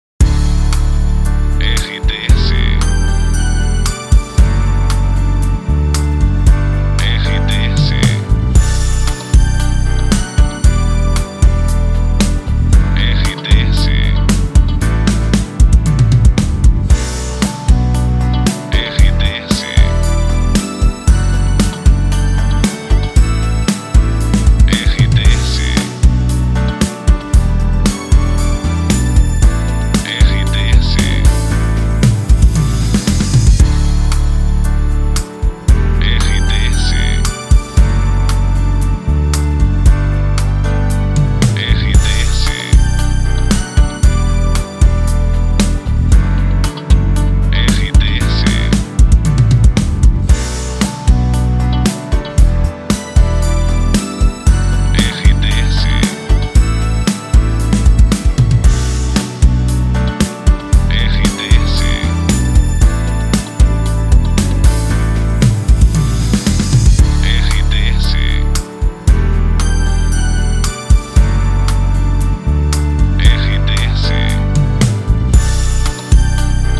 Trilha para locução